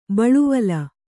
♪ baḷuvala